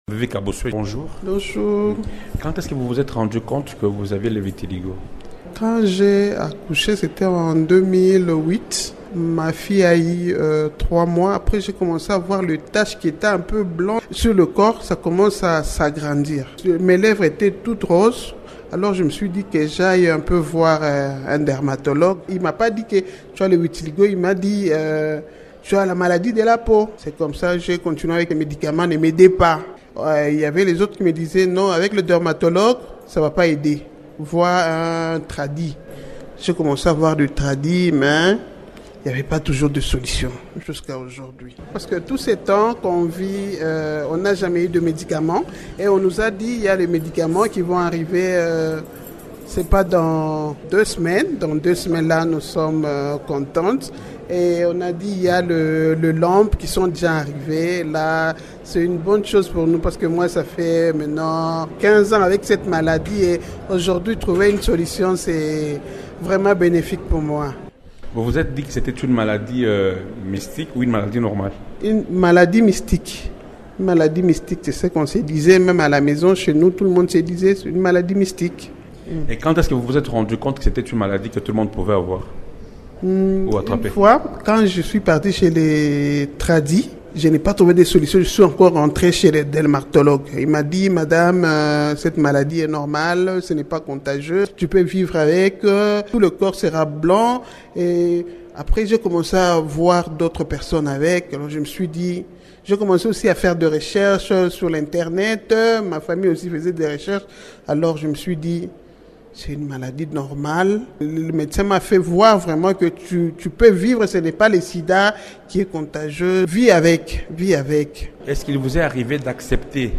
répond aux questions de